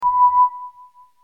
error_tone.mp3